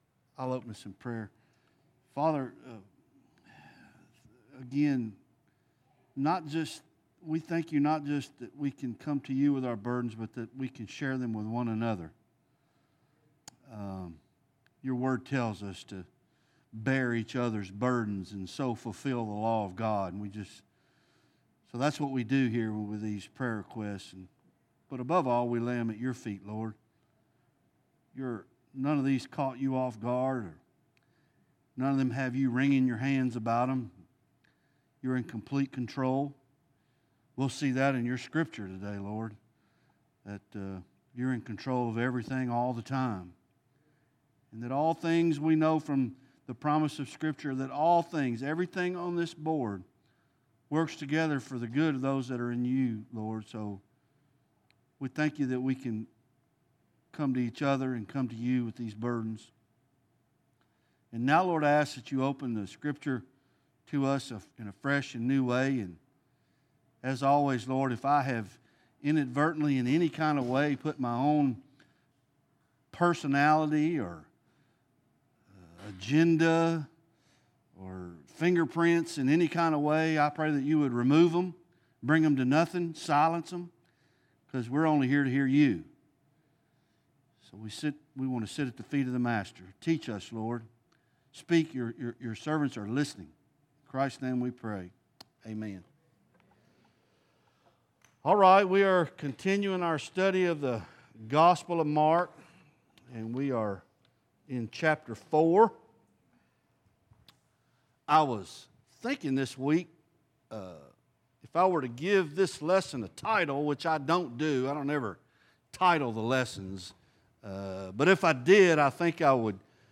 Bible Study Isaiah Ch 7